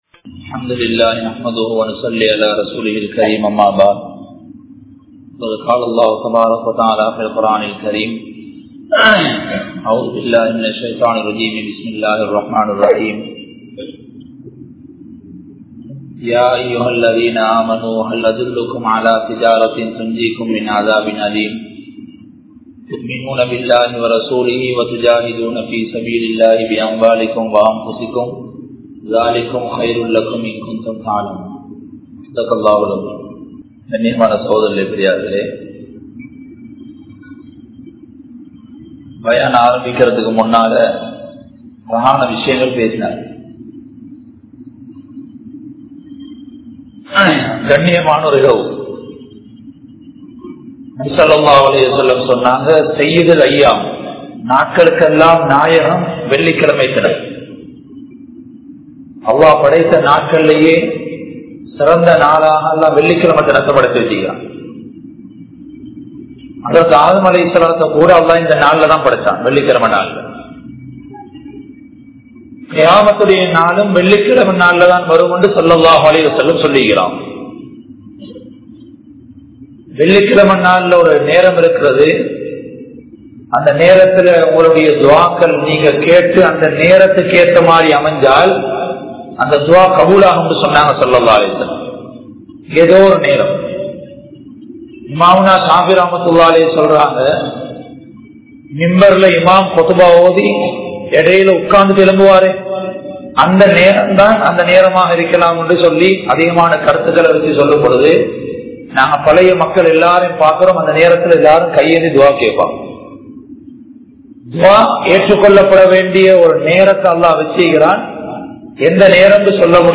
Success Through Da'wah | Audio Bayans | All Ceylon Muslim Youth Community | Addalaichenai
Negombo, Grand Jumua Masjith